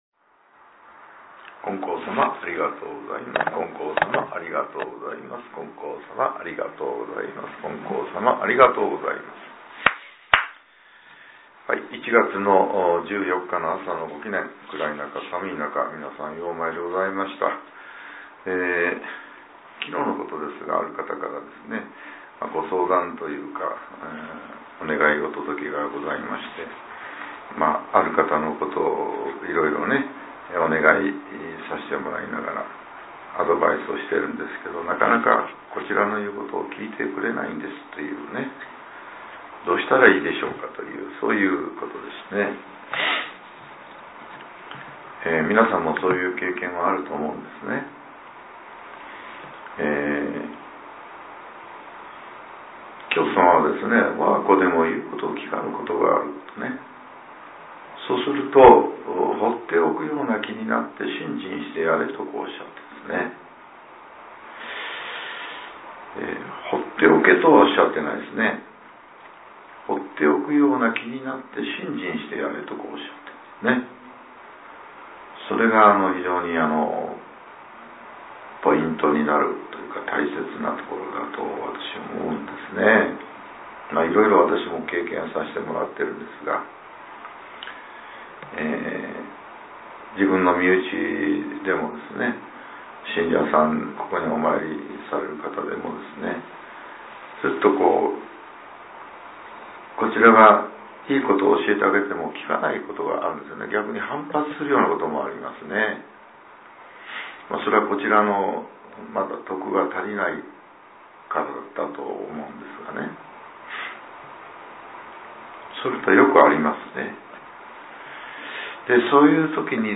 令和８年１月１４日（朝）のお話が、音声ブログとして更新させれています。